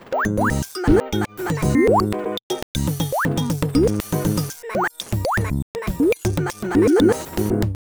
And a few crazy loops, mostly done with random kits and functions.